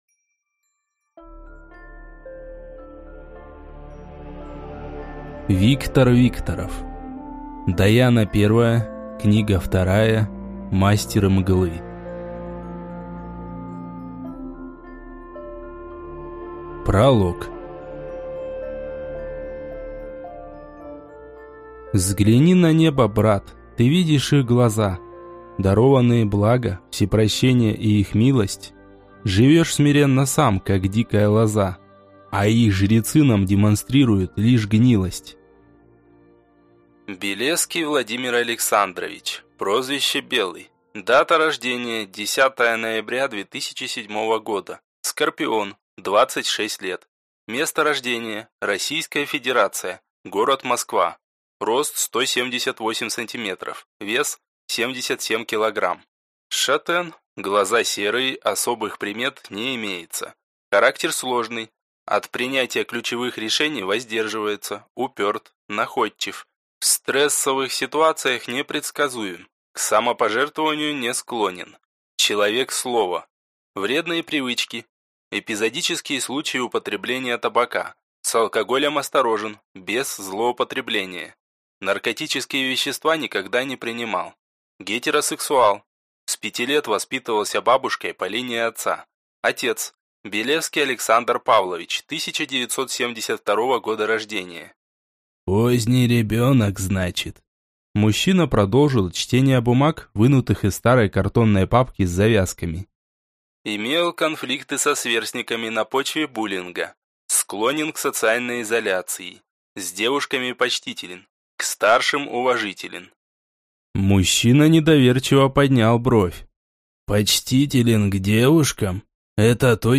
Аудиокнига Даяна I. Мастер Мглы | Библиотека аудиокниг